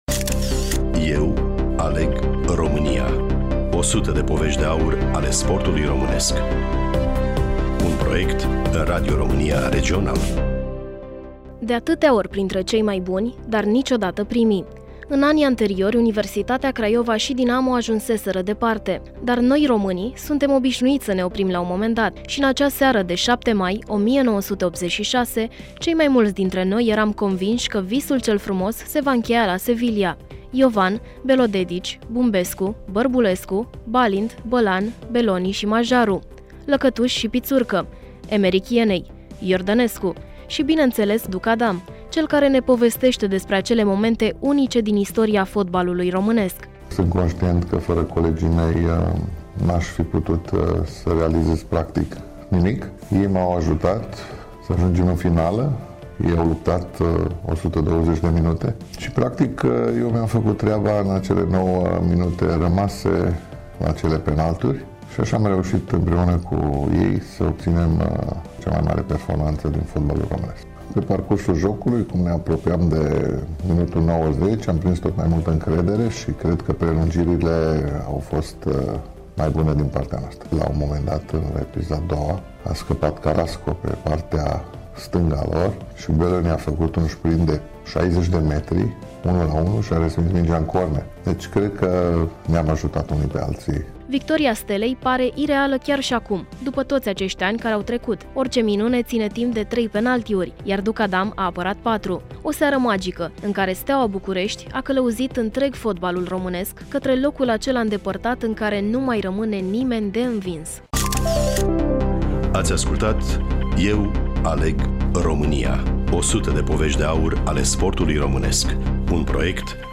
Avem doar niște băieți minunați, cu gânduri tricolore: Iovan, Belodedici, Bumbescu, Bărbulescu, Balint, Bălan, Boloni și Majearu; Lăcătuș și Pițurcă, Emerich Jenei, Iordănescu…și, bineînțeles pe Duckadam, cel care ne povestește despre acele momente unice din istoria fotbalului românesc.
Studioul: Bucuresti FM